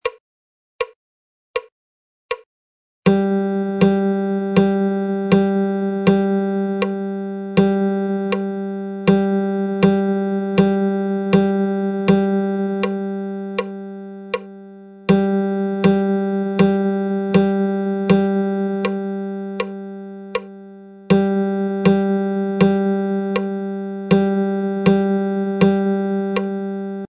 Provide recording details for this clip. Now onto our rhythm example chart below which introduces rest notation into our regular rhythm chart.